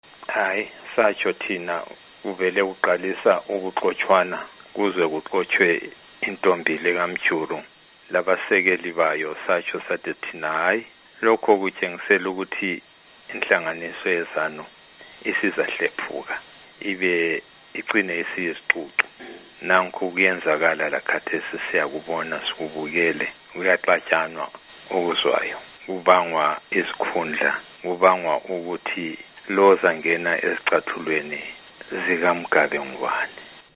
Ingxoxo loMnu. Dumiso Dabengwa